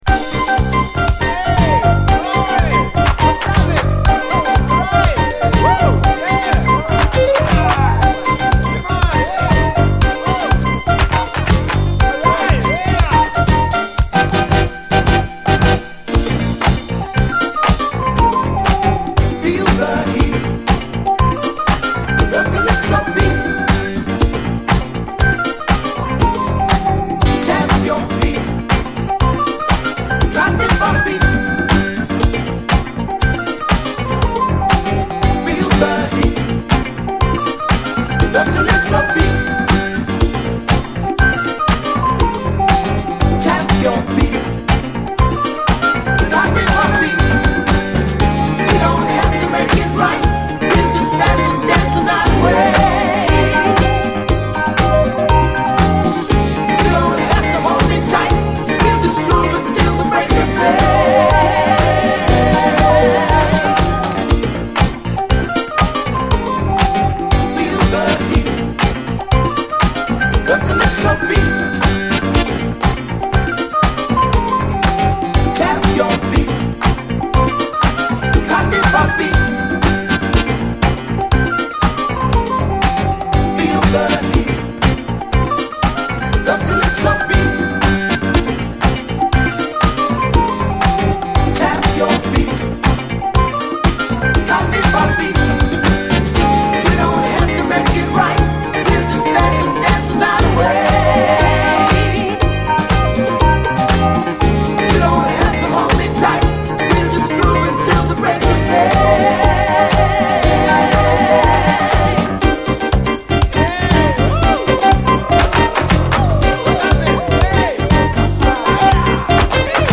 SOUL, DISCO
UKのブリット・ファンク・バンドが放った最高トロピカル・ブギー！